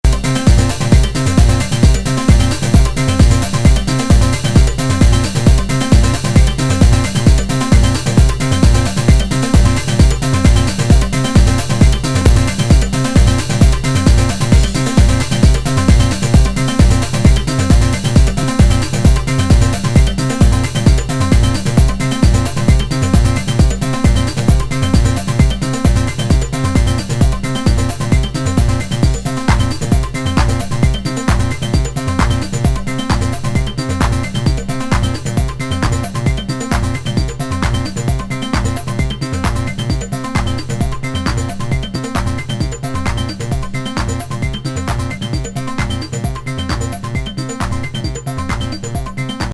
フロアユースなA面と、心地良すぎるチルでアンビエントな四つ打ちのB面。